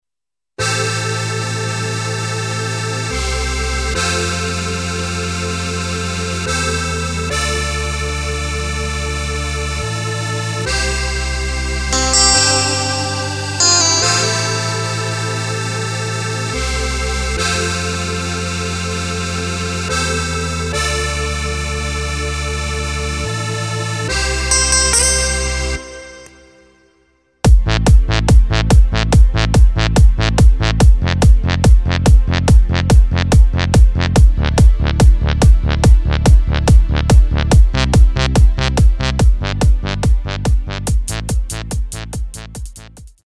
Takt:          4/4
Tempo:         143.00
Tonart:            Am
Playback mp3 Mit Drums